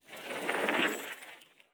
Foley Sports / Hockey / Ice Skate B.wav
Ice Skate B.wav